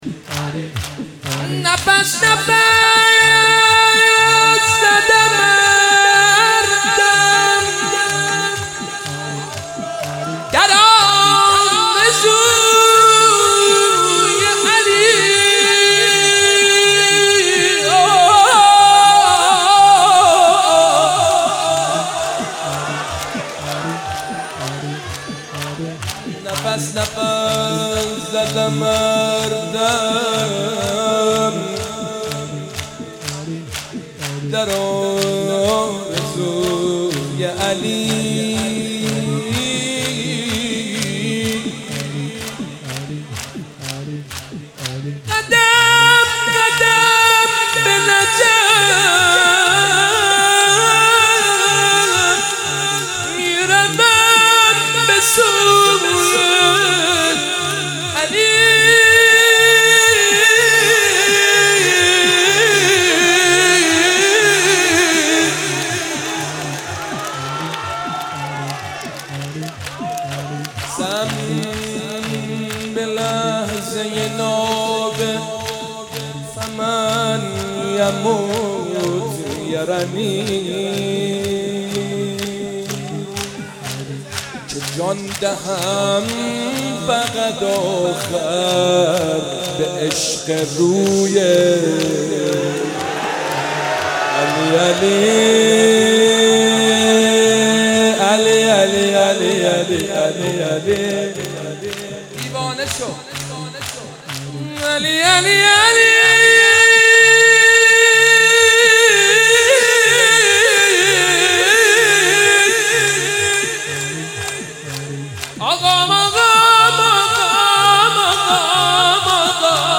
مناسبت : شب پنجم محرم
قالب : شور